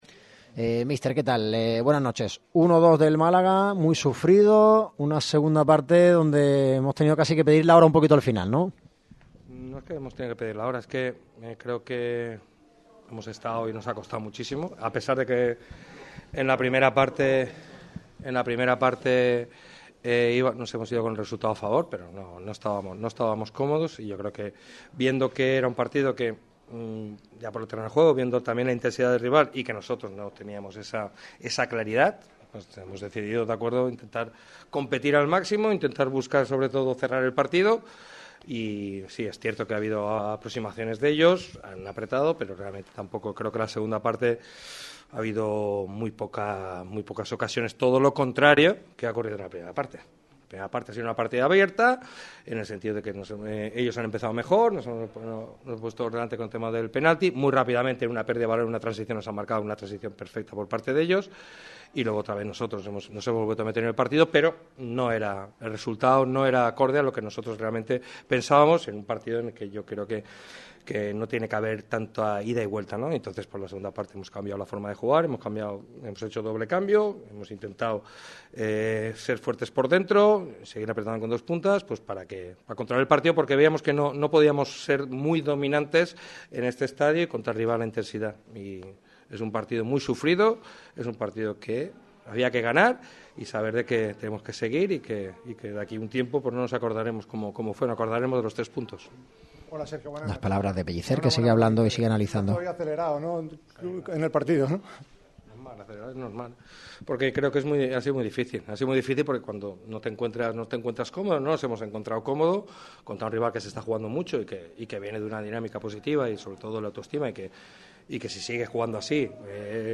El técnico del Málaga ha comparecido ante los medios tras la victoria malaguista sobre el Mérida (1-2). El entrenador ha dialogado sobre el encuentro y detalla las claves del mismo, además de valorar acciones concretas y opinar sobre algunos jugadores.